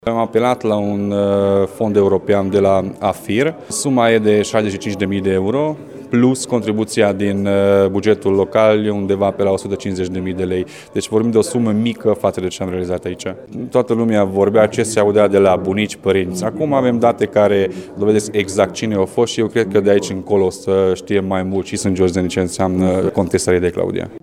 Primăria Sângeorgiu de Pădure care administrează castelul Rhedey lucrează de doi ani la amenajarea muzeului care să pună în valoare personalitatea Contesei Claudia. Primarul Csibi Attila Zoltán: